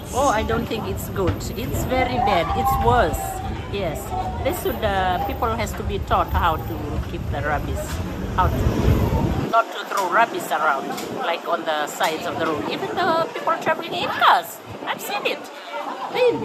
A female market vendor